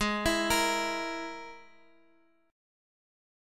Listen to G#sus2#5 strummed